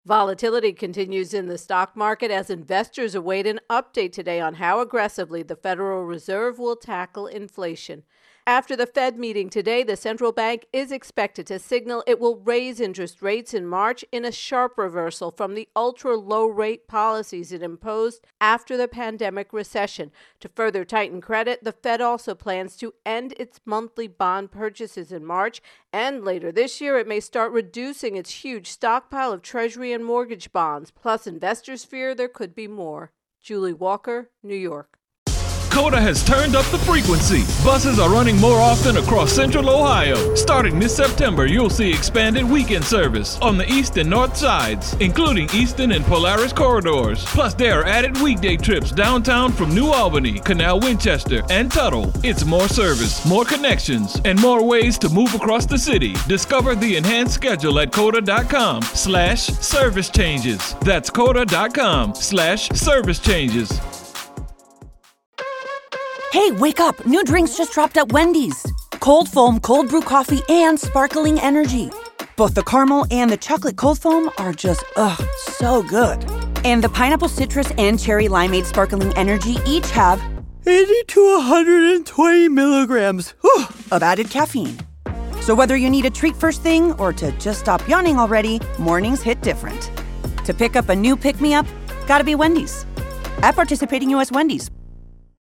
Federal Reserve intro and voicer